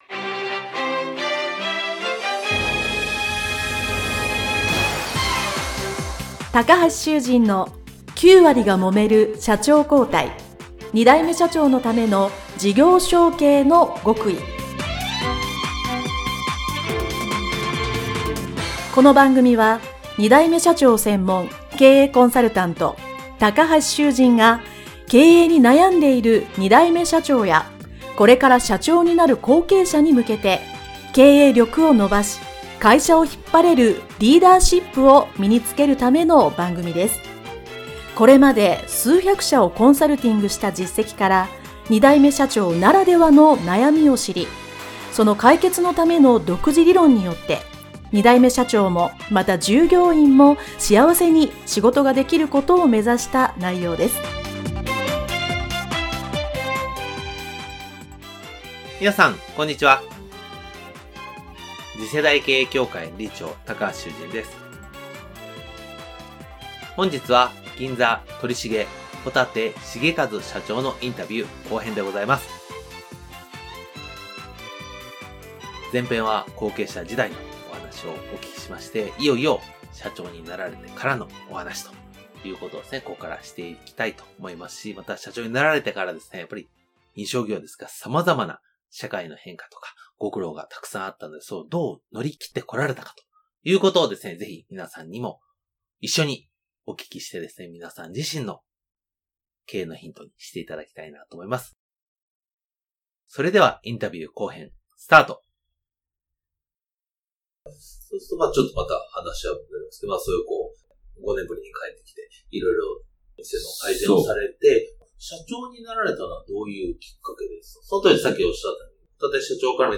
【インタビュー後編】